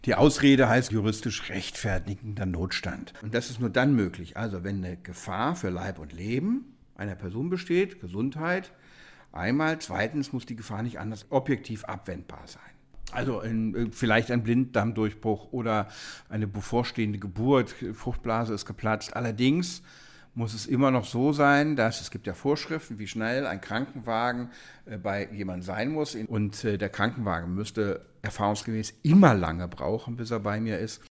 Auto, DAV, O-Töne / Radiobeiträge, Ratgeber, Recht, , , , , , , ,